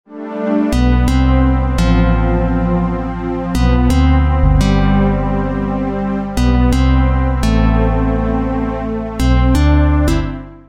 Короткие мелодии для SMS [9]
Скачать. Мелодия. Для SMS - протяжная. mp3 звук